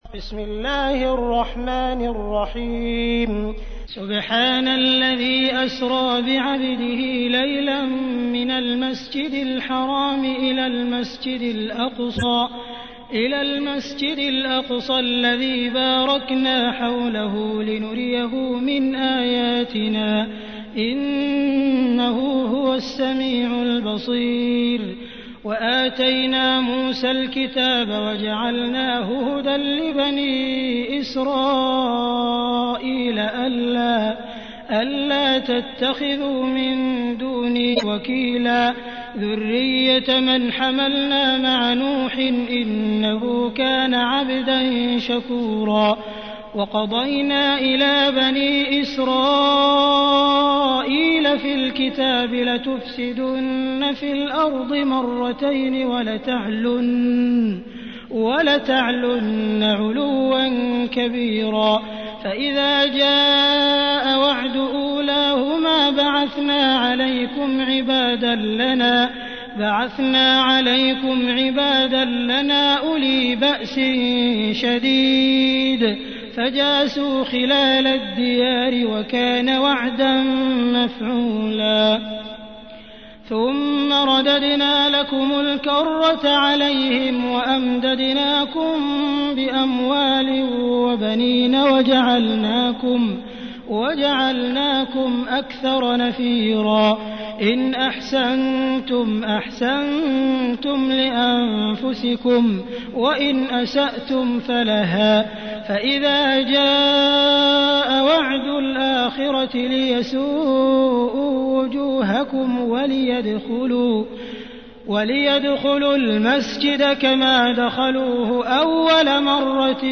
تحميل : 17. سورة الإسراء / القارئ عبد الرحمن السديس / القرآن الكريم / موقع يا حسين